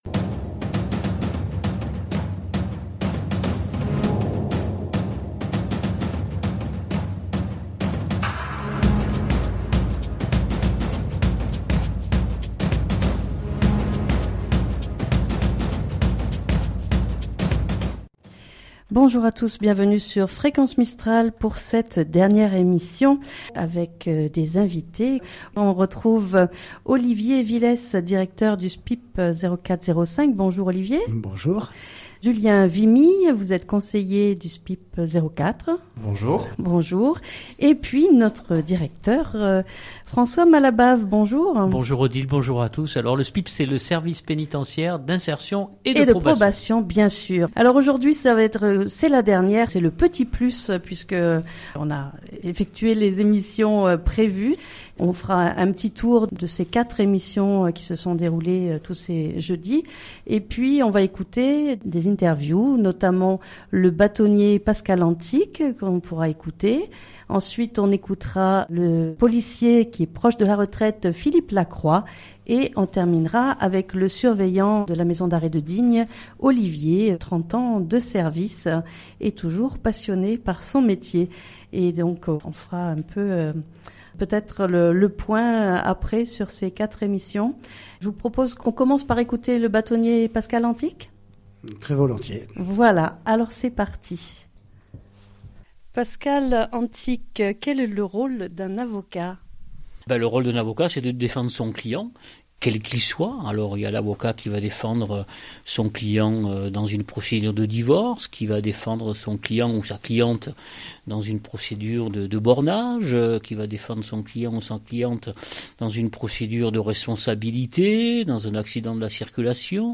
Cette émission est un bilan des émissions précédentes où l'on écoutera plusieurs interviewes qui seront ensuite commentées par nos invités.